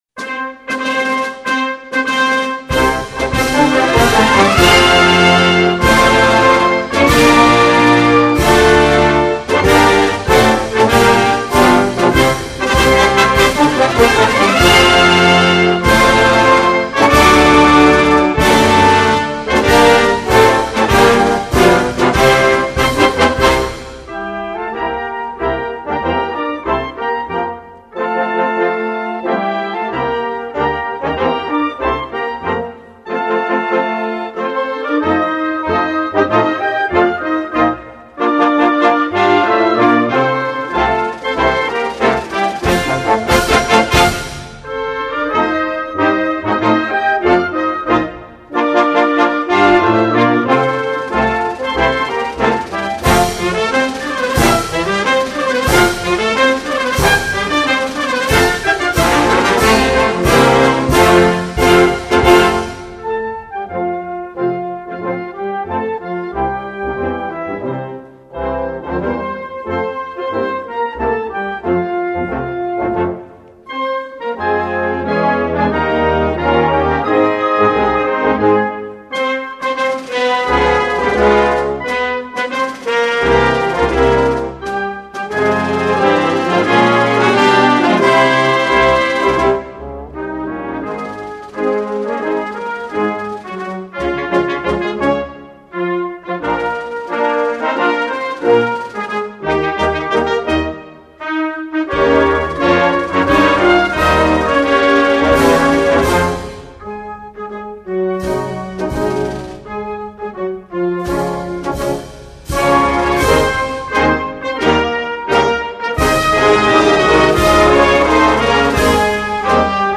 Peru-Anthem.mp3